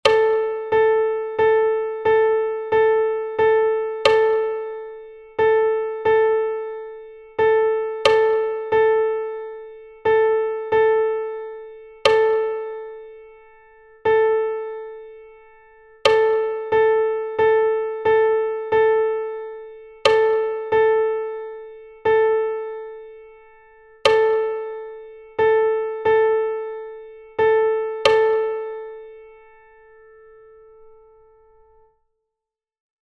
Rhythmic dictation
dictado_ritmico_6_8.mp3